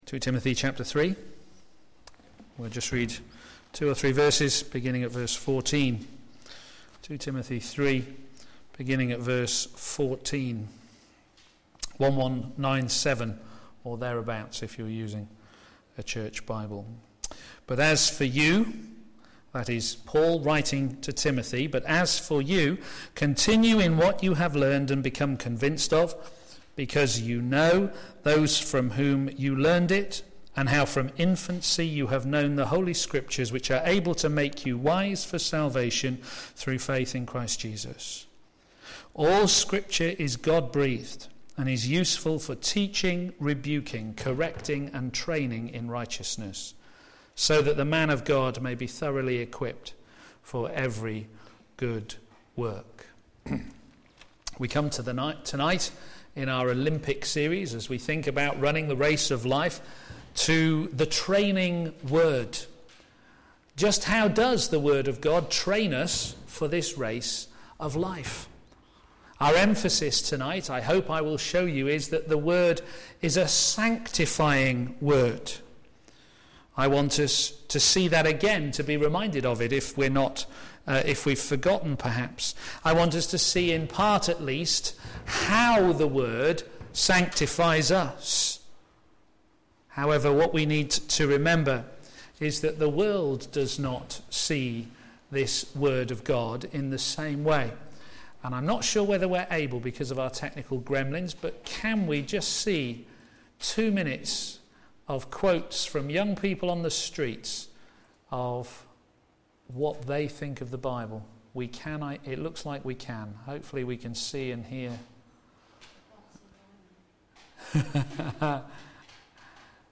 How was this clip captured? p.m. Service